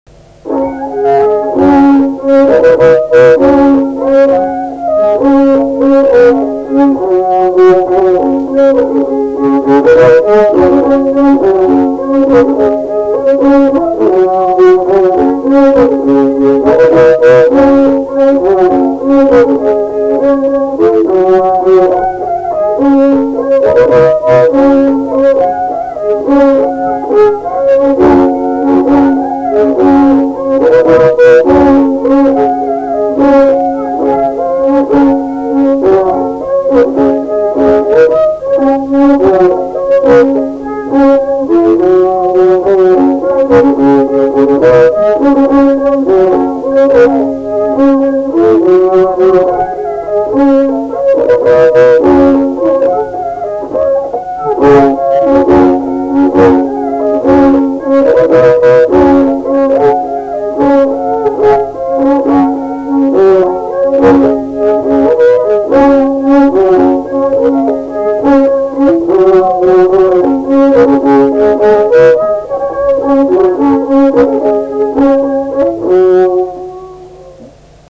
Mazurka
šokis